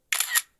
camera.ogg